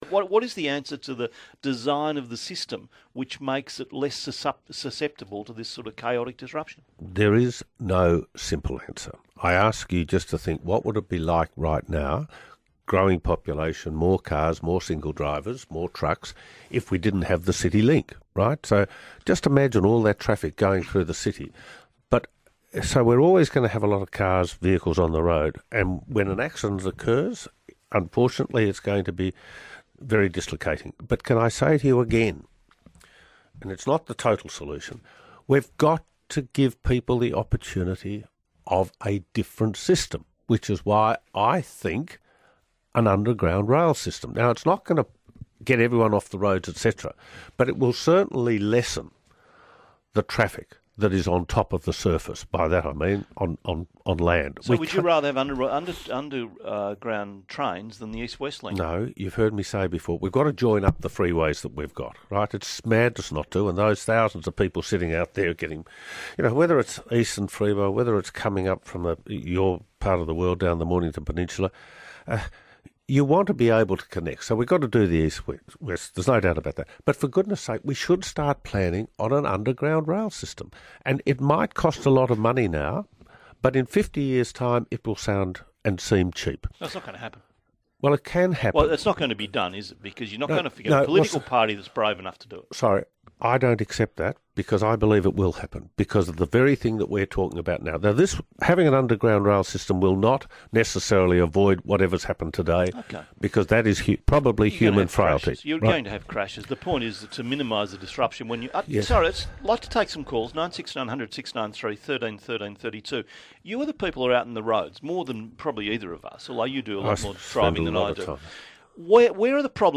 Jeff Kennett speaks to Neil Mitchell